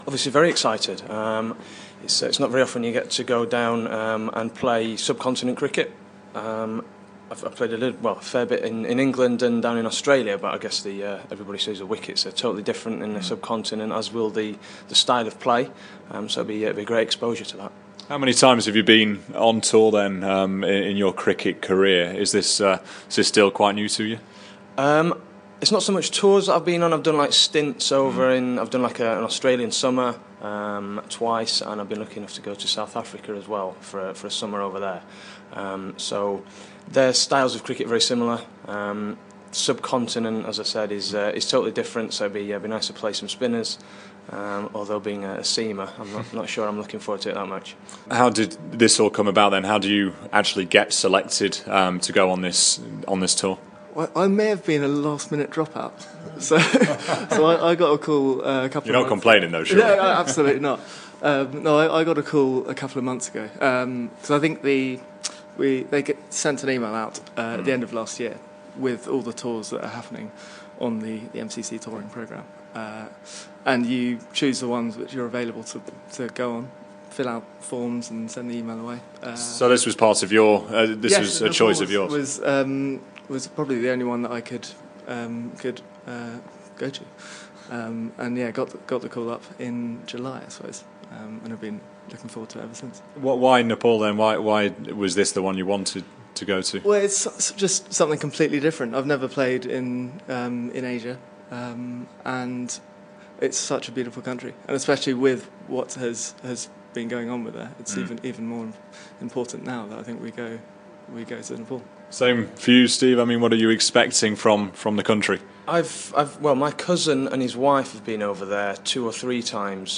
INTERVIEW: Hampstead's MCC representatives speak to BBC Radio London before flying out to tour Nepal